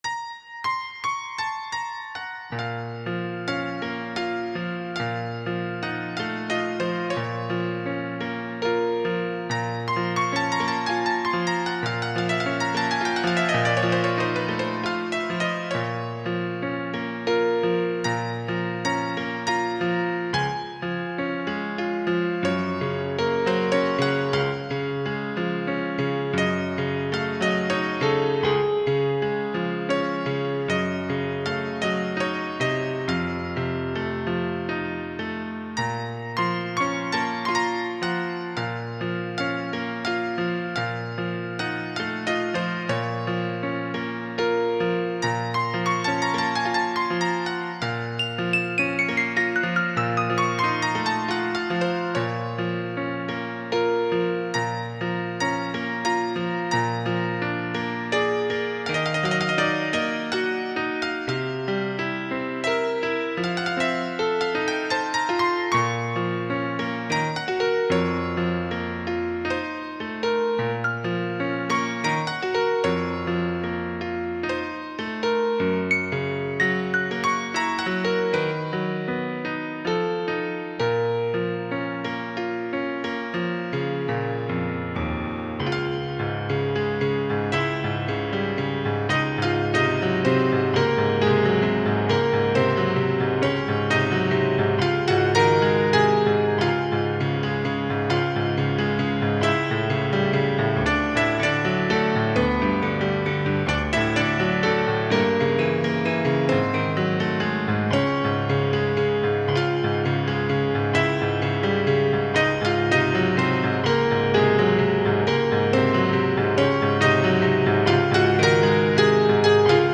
pianoforte
nocturno
romanticismo
romántico